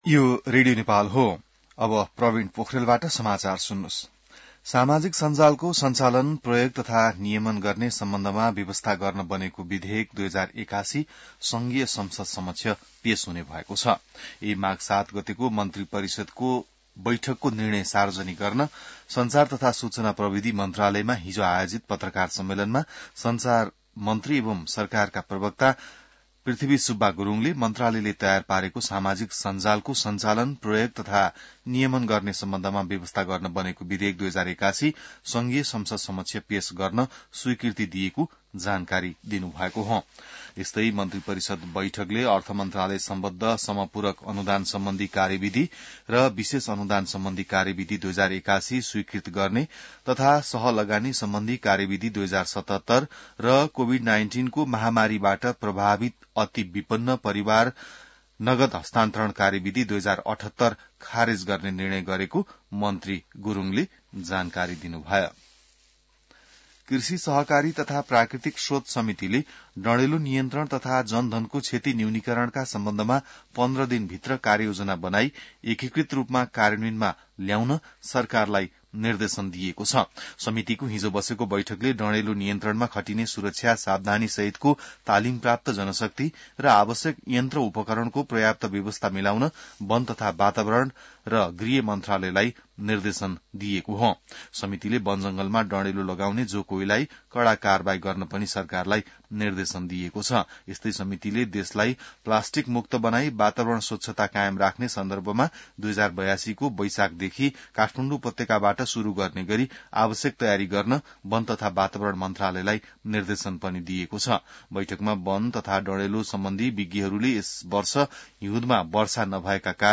बिहान ६ बजेको नेपाली समाचार : ११ माघ , २०८१